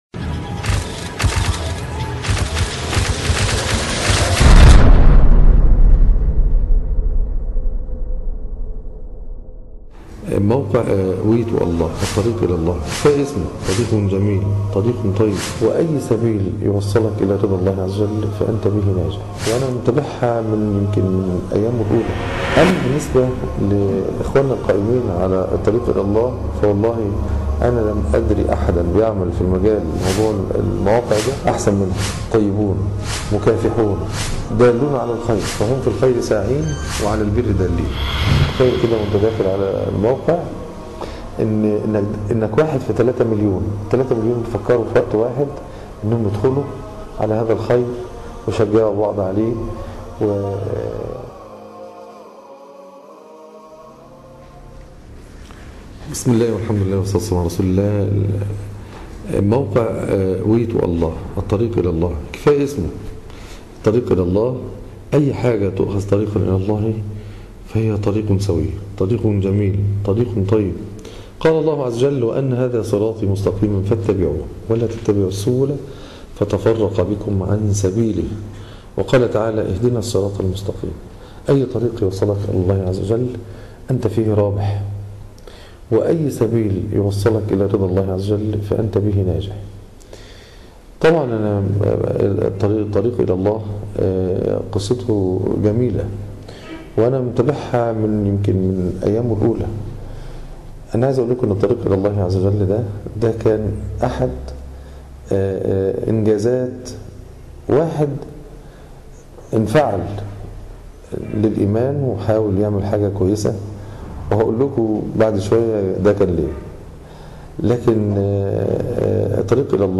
كلمة شكر من الدعاة والمشايخ